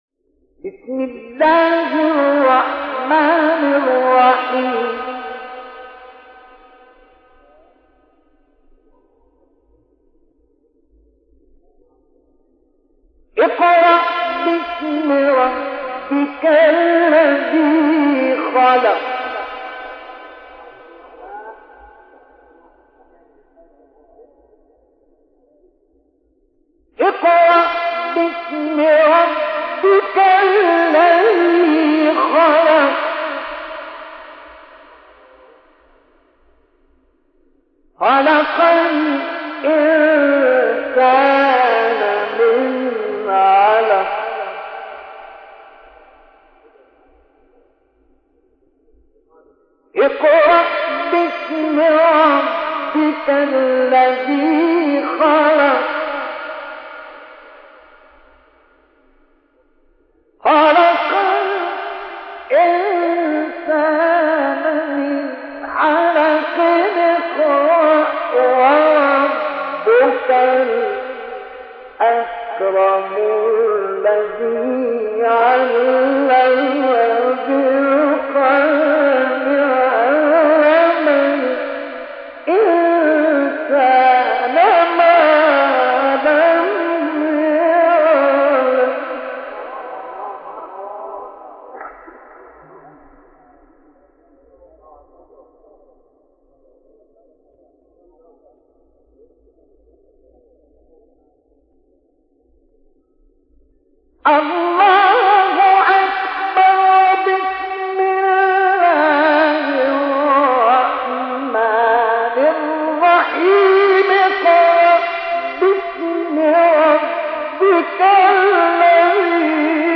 آیات ابتدایی سوره علق استاد مصطفی اسماعیل | نغمات قرآن | دانلود تلاوت قرآن